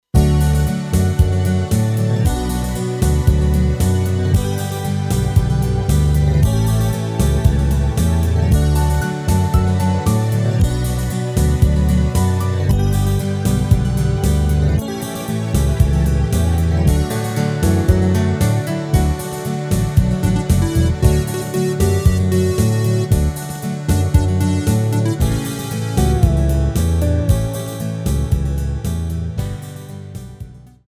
Rubrika: Folk, Country
HUDEBNÍ PODKLADY V AUDIO A VIDEO SOUBORECH